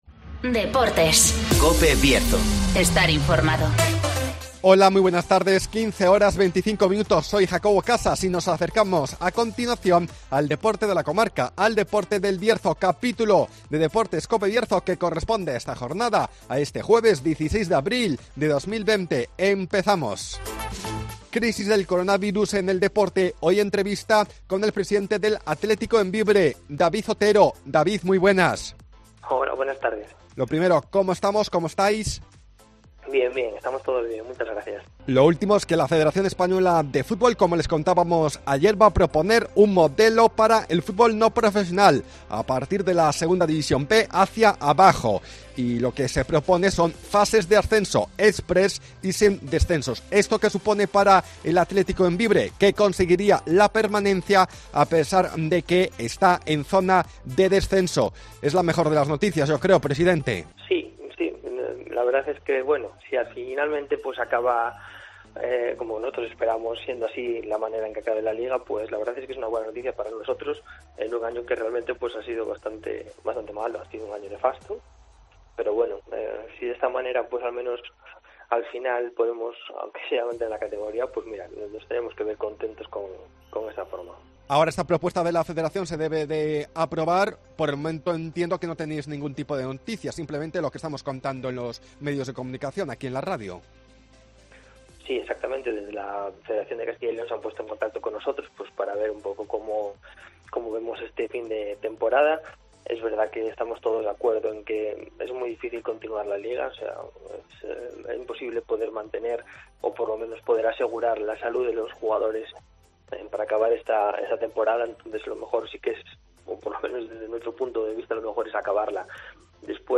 -Crisis del coronavirus -Entrevista